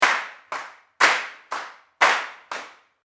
The first beat gets the emphasis, and the second beat is the weaker one:
2/2 time clap
The resulting accent pattern of one-two is reminiscent of a ticking clock, or a march.
2_2-clap-120-BPM.mp3